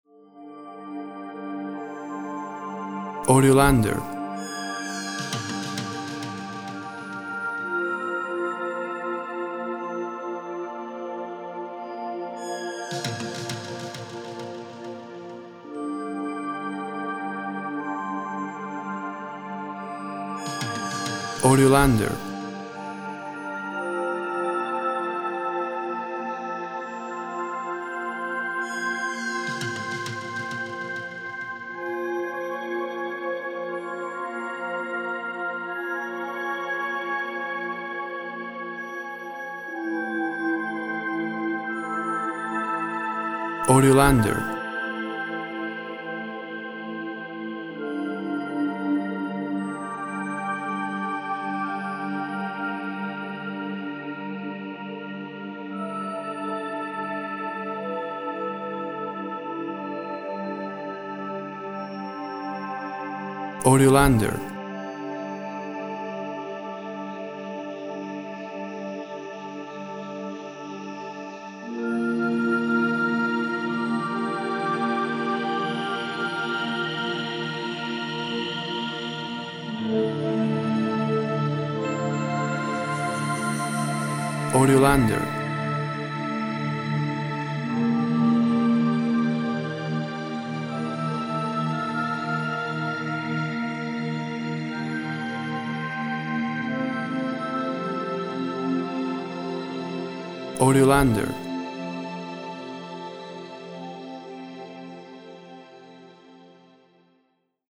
Tempo (BPM) 58